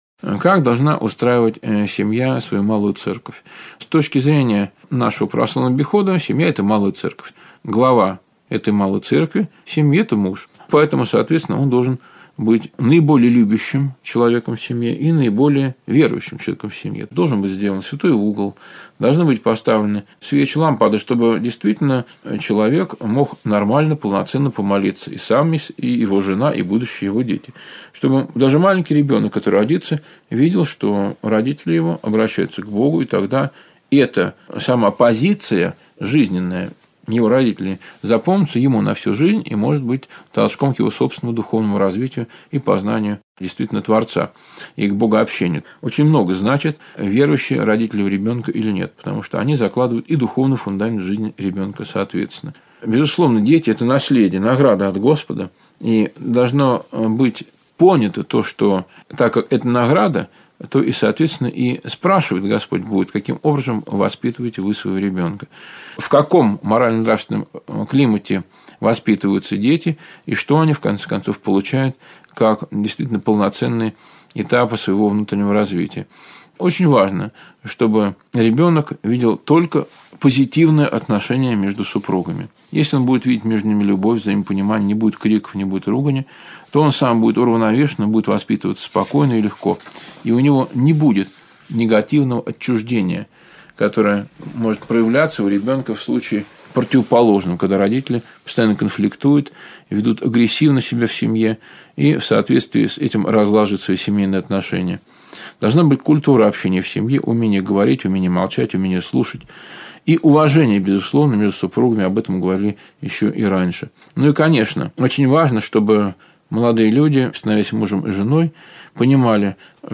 Заповедь Беседы Нравственность Брак Семья